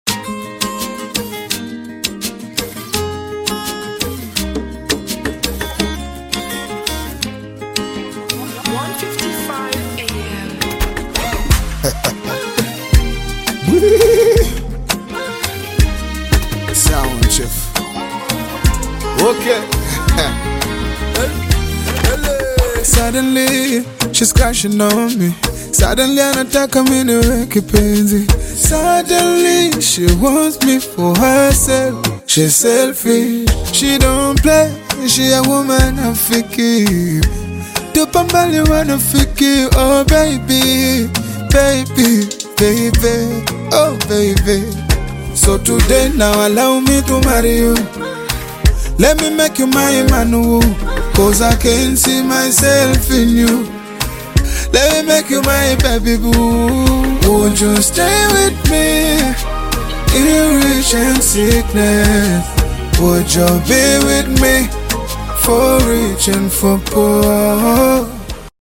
rap single